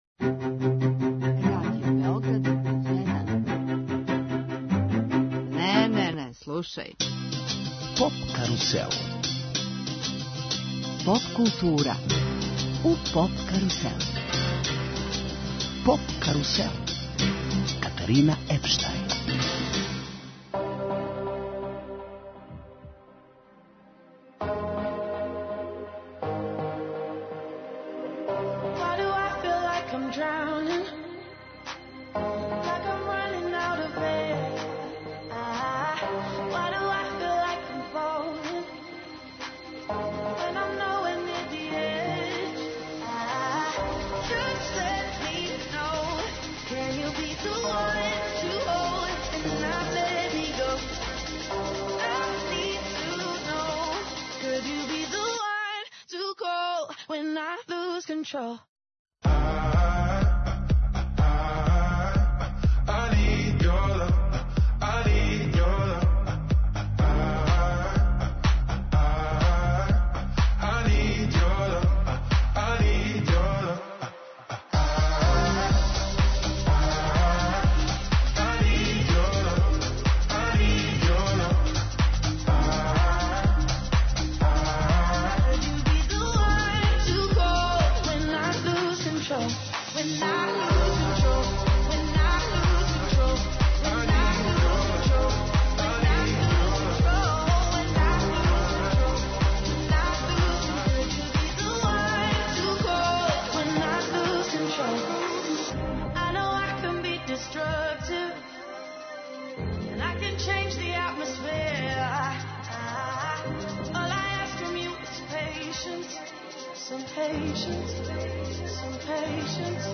Поводом двадесет година фестивала Егзит, програм емитујемо уживо са Петроварадинске тврђаве.